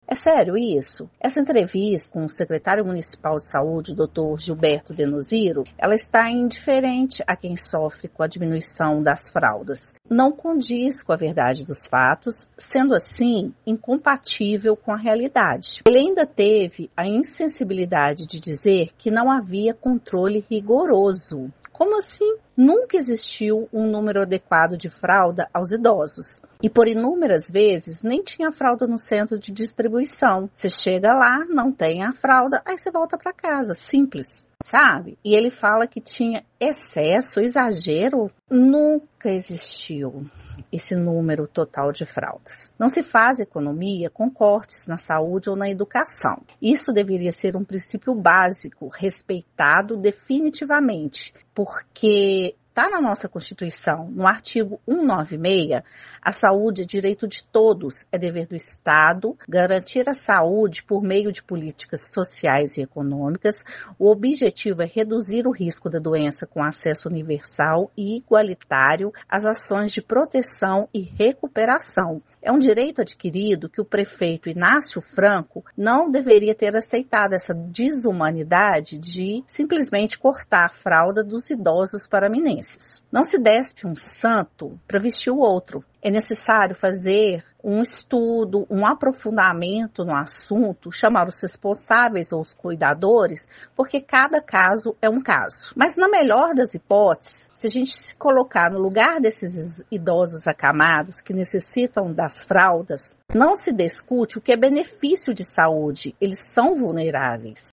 Filha de pessoa acamada